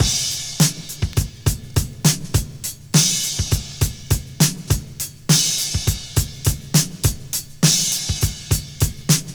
Free breakbeat - kick tuned to the D# note. Loudest frequency: 1642Hz
• 103 Bpm Drum Loop A Key.wav
103-bpm-drum-loop-a-key-rVD.wav